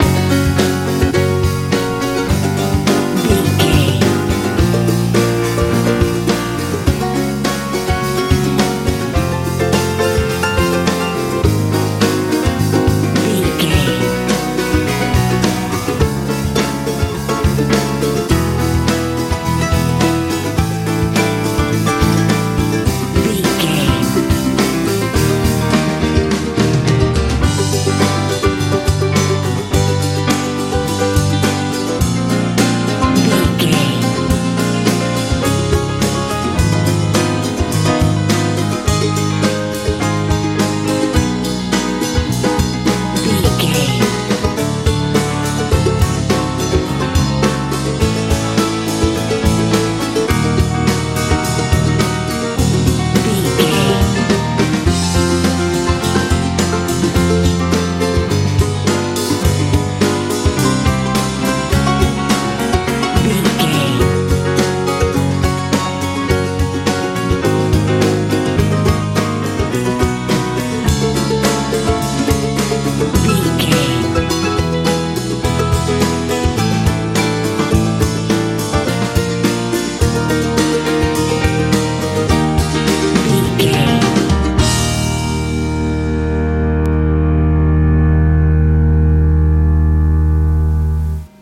med pop country feel
Ionian/Major
F♯
cool
groovy
piano
acoustic guitar
bass guitar
drums
bright
cheerful/happy